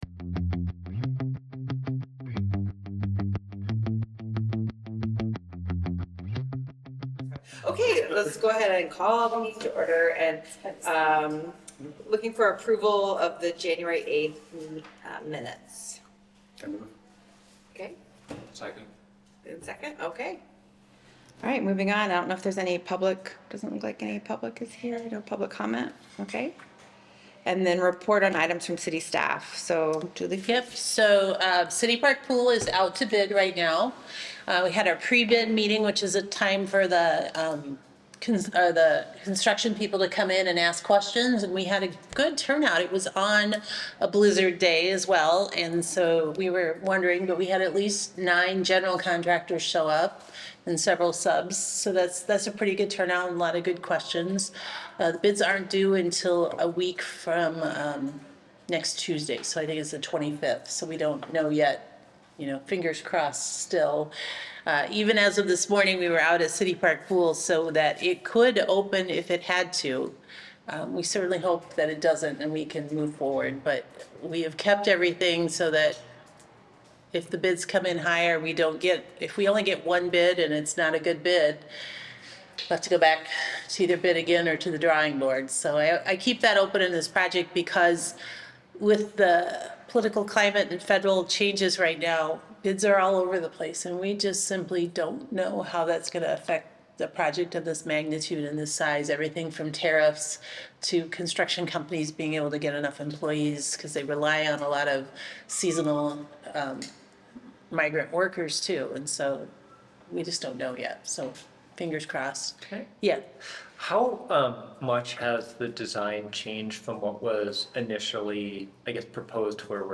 A meeting of the City of Iowa City's Parks and Recreation Commission.